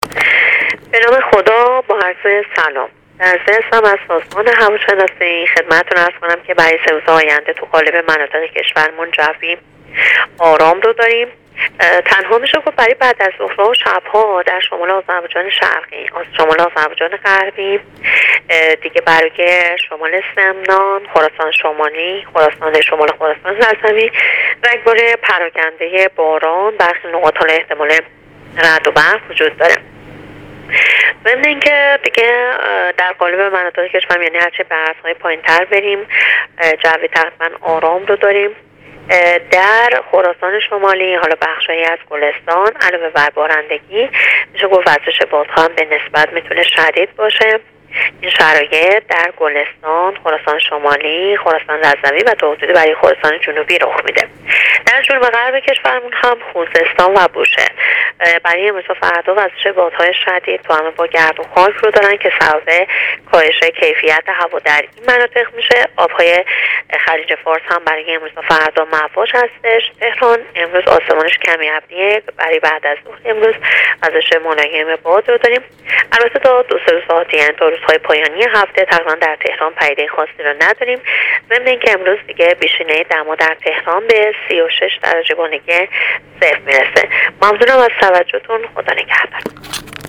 گزارش آخرین وضعیت جوی کشور را از رادیو اینترنتی پایگاه خبری وزارت راه و شهرسازی بشنوید.